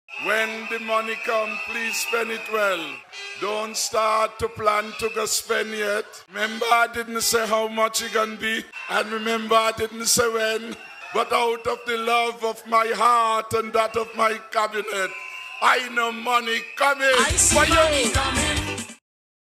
PM Harris, who is also the Minister of Finance, urged all to be financially-prudent:
Prime Minister, Dr. Timothy Harris.